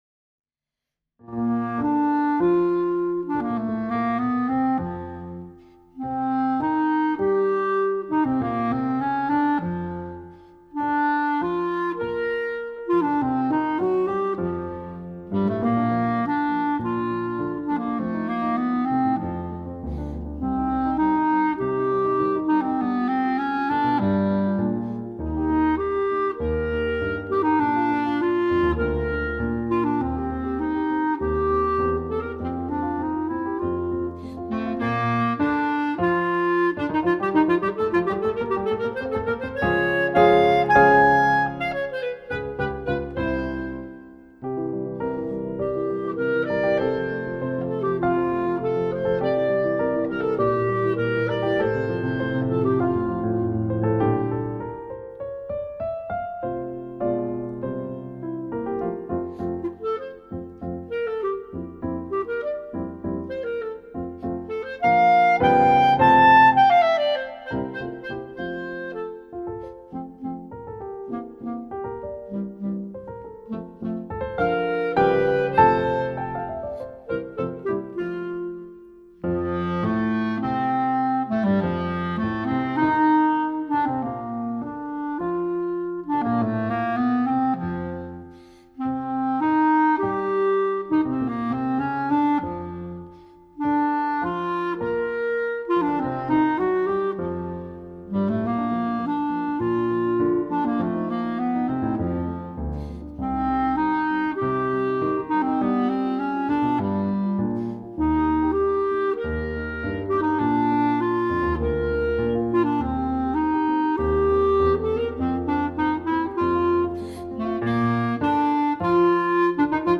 Voicing: Clarinet Solo with Piano Accompaniment